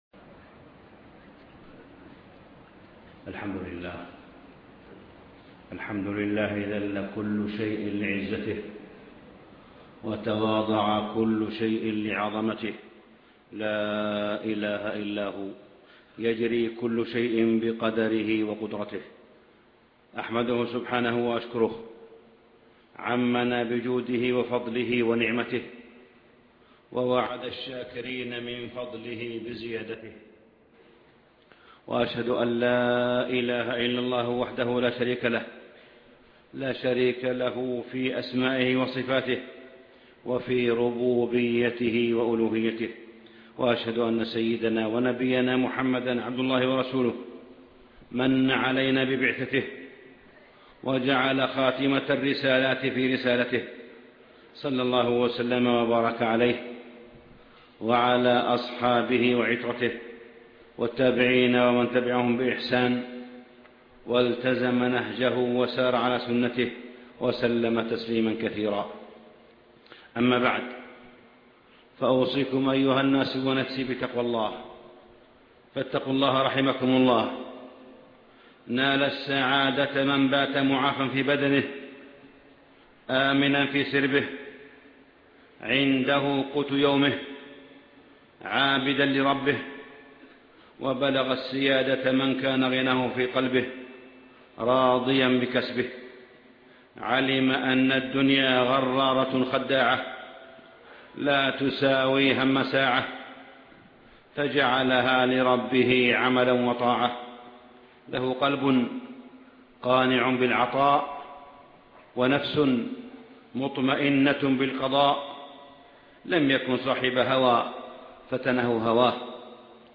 من هو الموفق-السعادة والطريق إلى الله ( 24/1/2014) خطب الجمعة - الشيخ صالح بن حميد